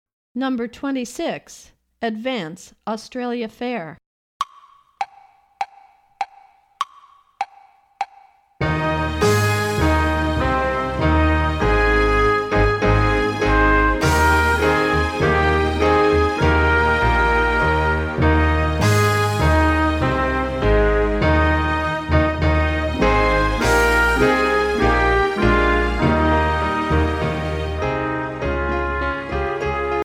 Voicing: Drums